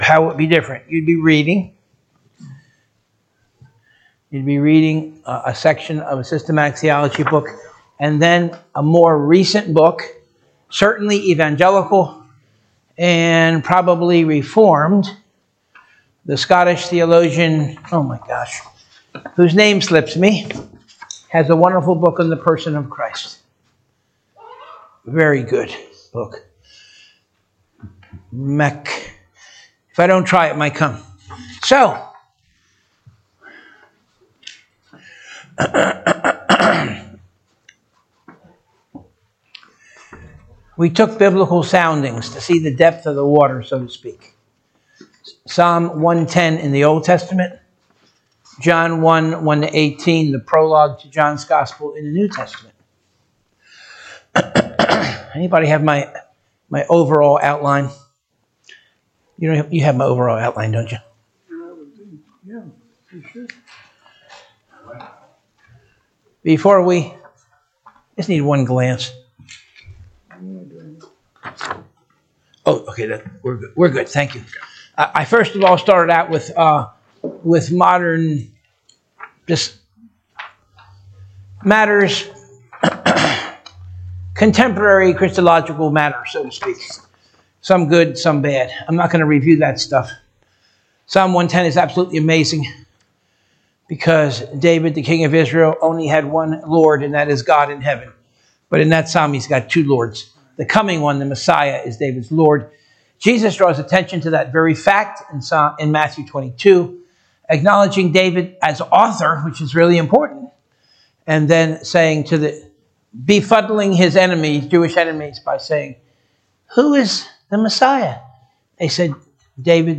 Sunday School 12/22/2024 - Covenant of Grace Church
Preacher